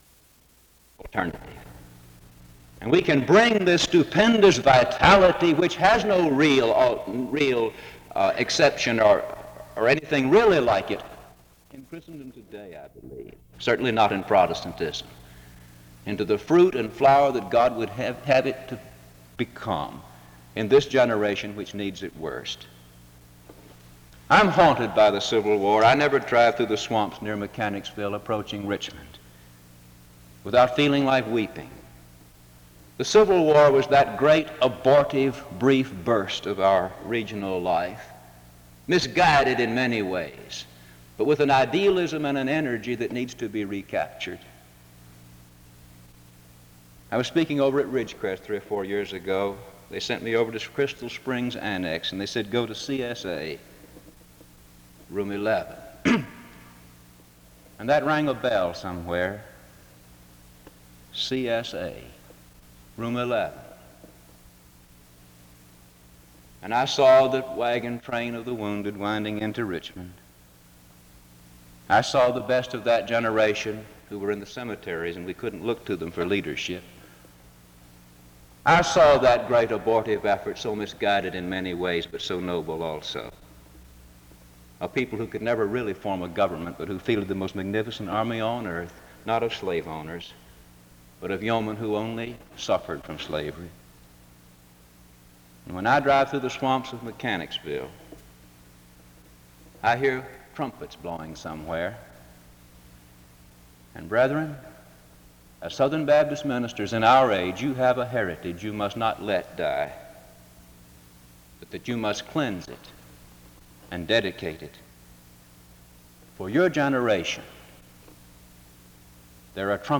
SEBTS Chapel
• Wake Forest (N.C.)